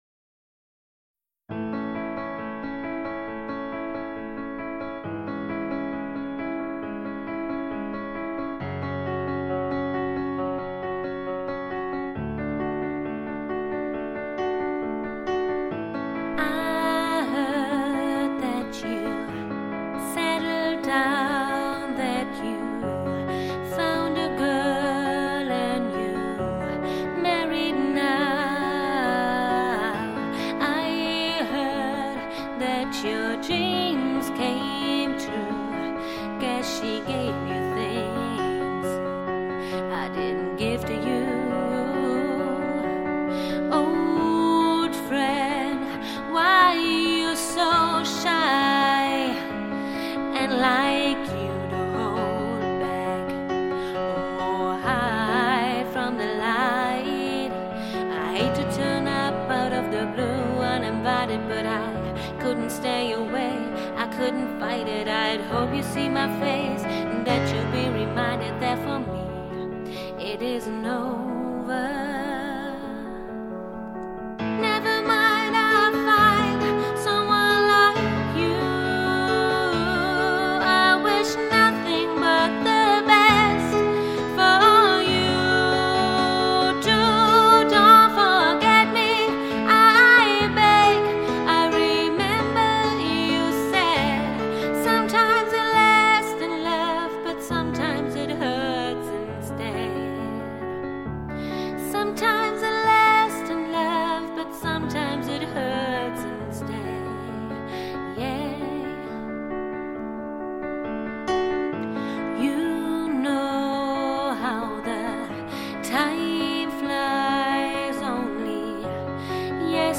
• Sänger/in